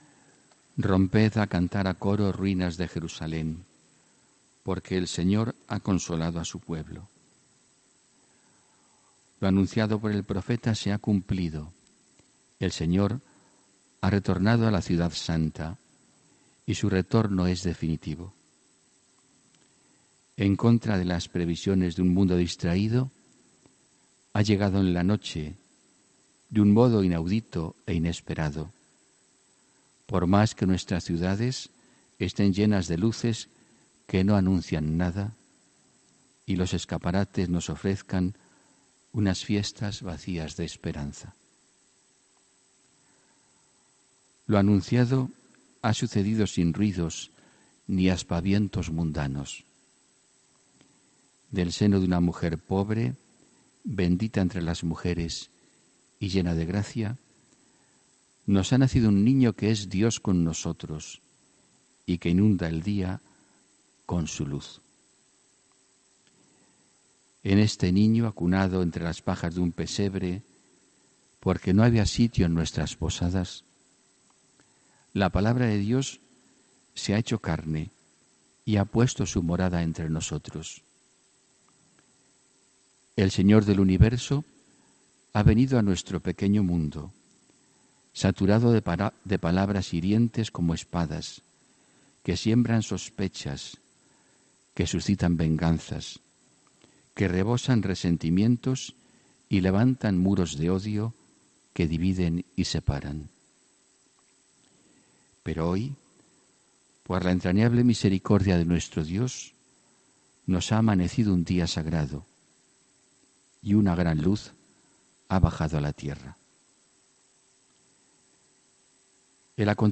HOMILÍA 25 DICIEMBRE 2018